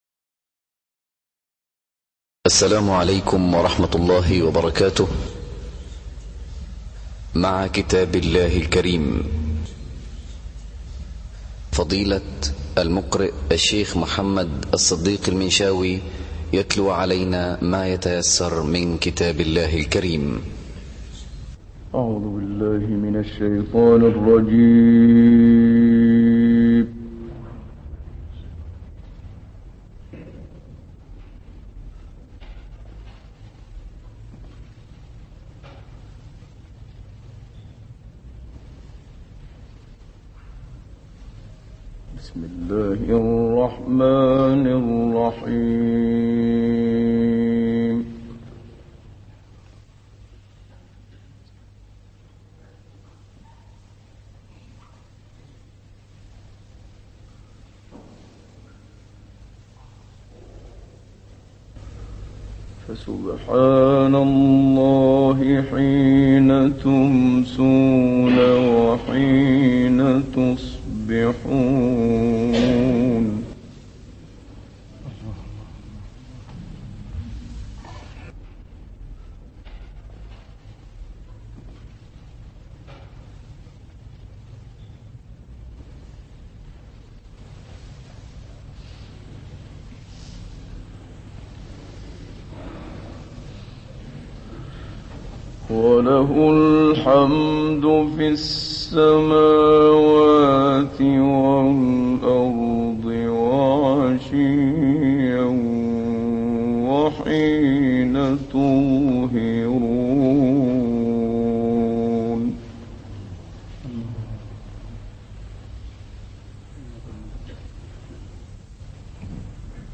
تهران - الکوثر : استاد منشاوی این تلاوت زیبا را که آیات 17 تا 54 سوره روم را شامل می شود در لیبی اجرا کرده است.